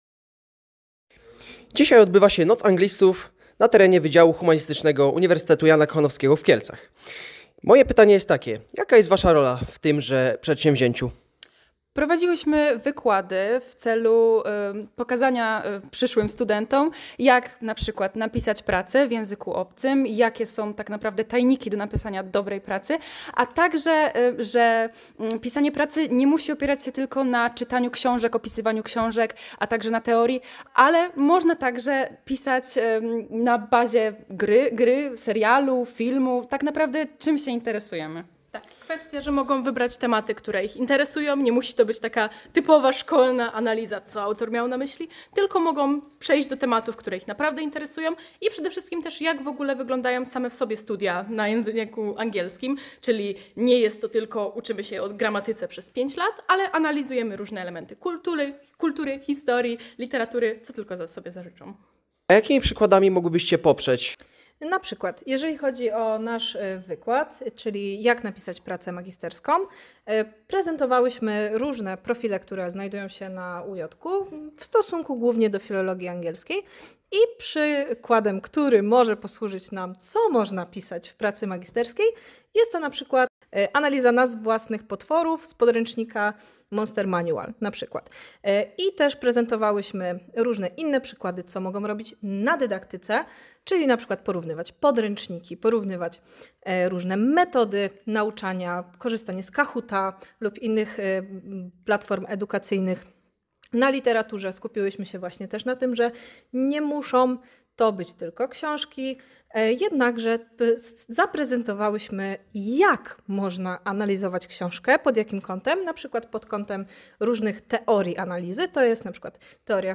Zapytaliśmy studentki piątego roku, jedne z prelegentów na Nocy Anglistów o wrażenia z ich wykładu nt. „How it’s made: writing a thesis from scratch with MA students” (tłum. Jak to się robi: pisanie pracy dyplomowej od zera ze studentkami studiów magisterskich) oraz przykłady zastosowania wskazówek podanych w prelekcji.